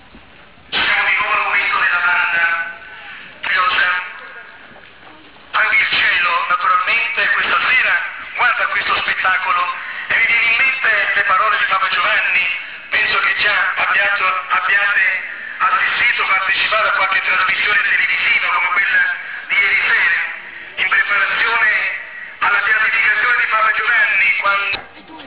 procession_chapelle_test.wav